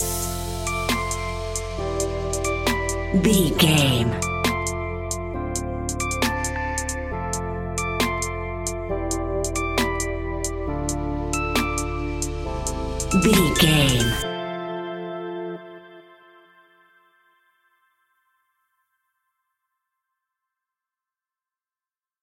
Aeolian/Minor
hip hop
laid back
Deep
hip hop drums
hip hop synths
piano
hip hop pads